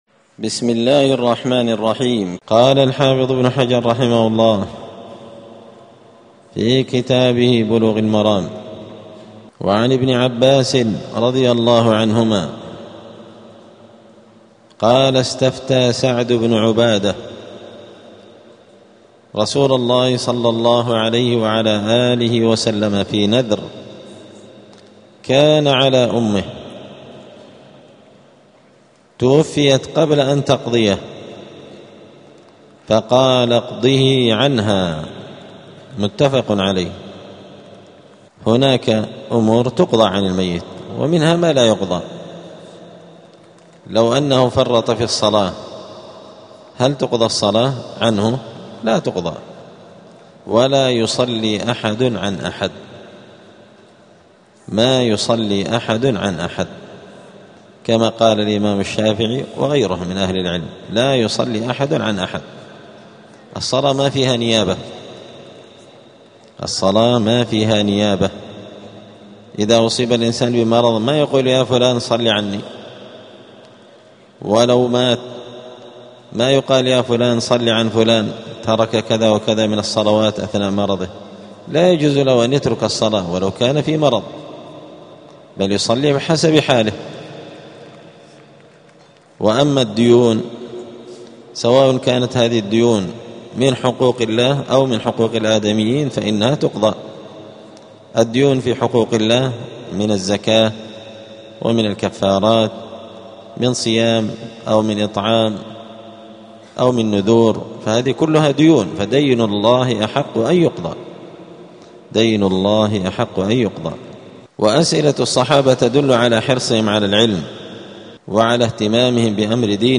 *الدرس السابع عشر (17) {ﻭﻓﺎء ﻧﺬﺭ اﻟﻤﻴﺖ}*
دار الحديث السلفية بمسجد الفرقان قشن المهرة اليمن